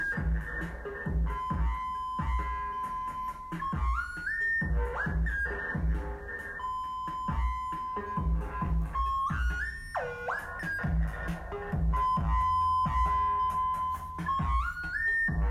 Just hooked it up, liking the focussed dist circuit on the tr8 tb3 combo. It really destroys the sound in a lovely hard techno way.
Note. The badly recorded clip is actually using the mid drive setting which give a lovely warmth